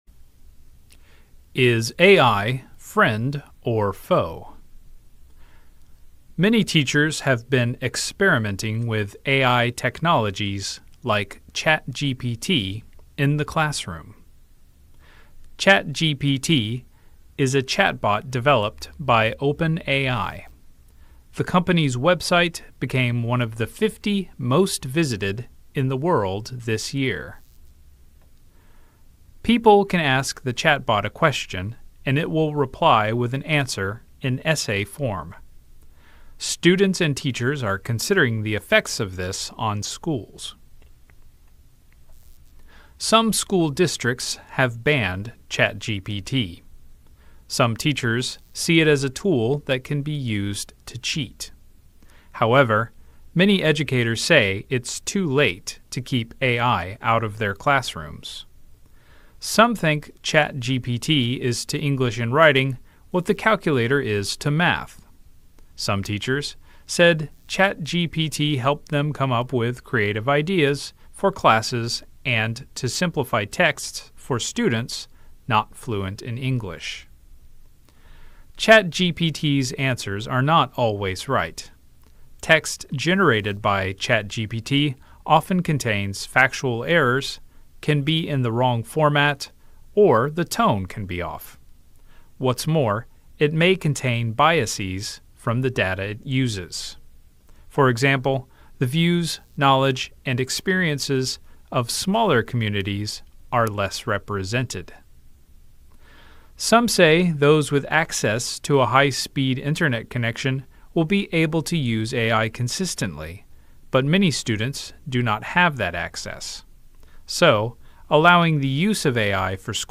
朗讀